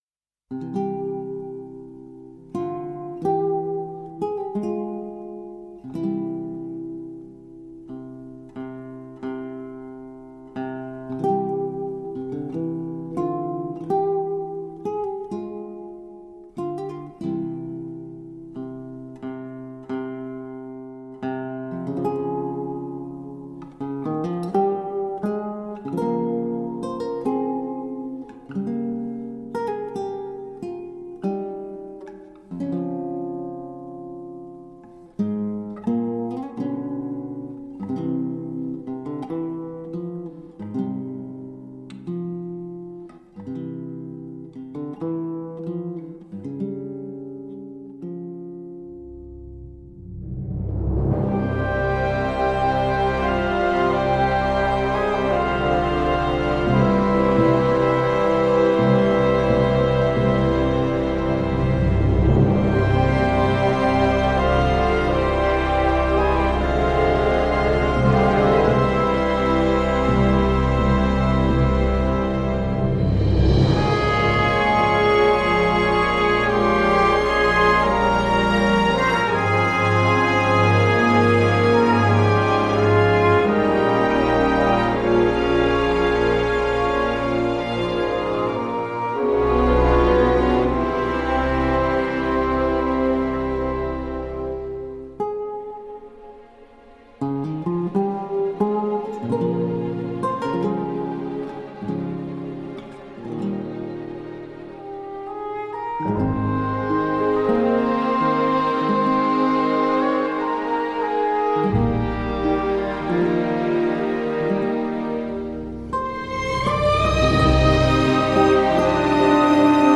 La partition est sombre, tragique et s’illumine rarement.